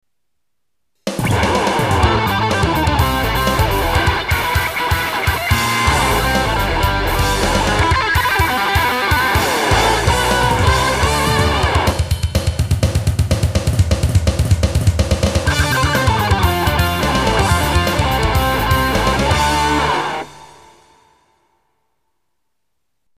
guitar instrumental
このページの曲はすべてコピー（またはｶｳﾞｧｰ）です。